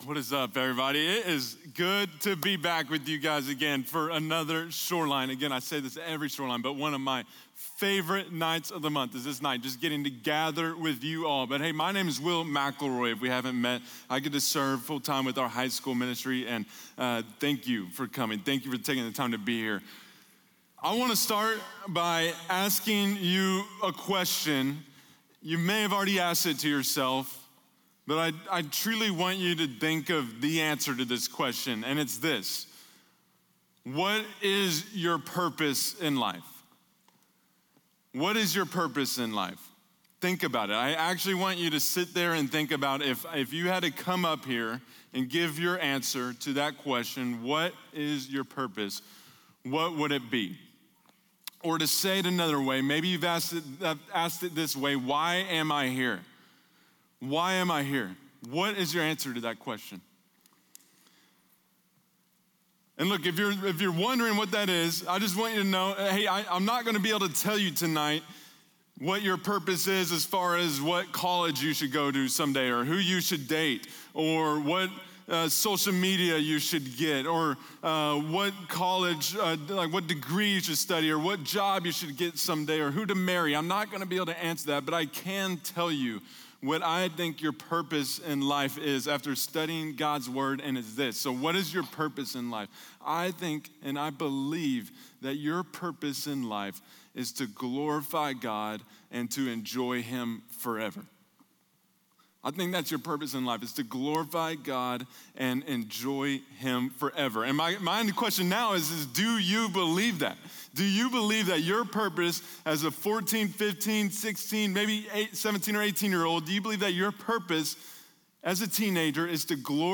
Messages